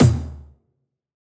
taiko-soft-hitfinish.ogg